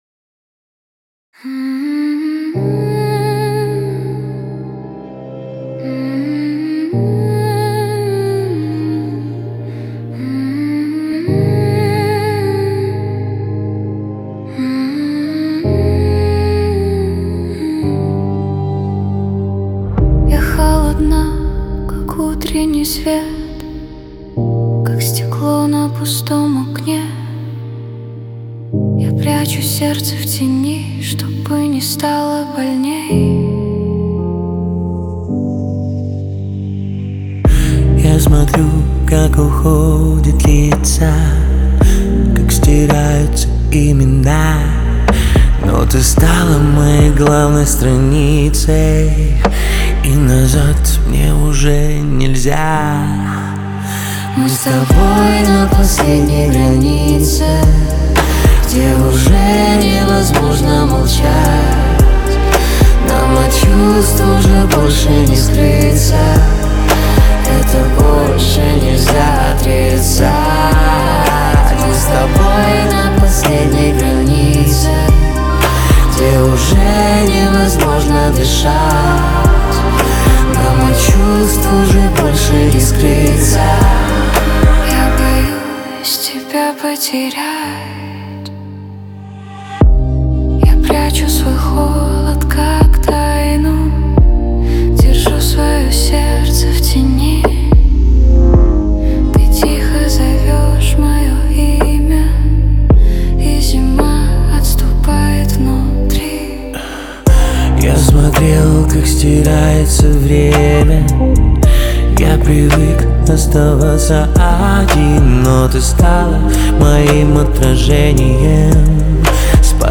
Рейв